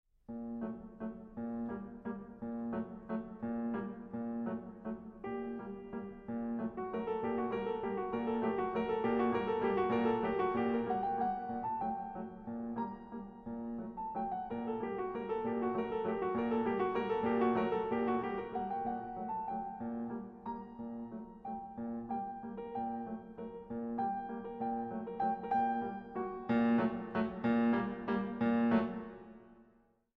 Piano
Trackdown Studios